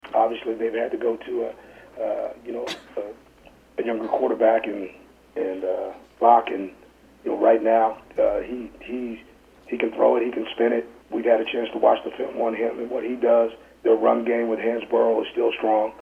Sound from head coach of Vanderbilt Derek Mason